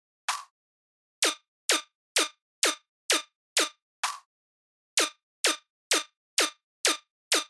BASS HOUSE KITS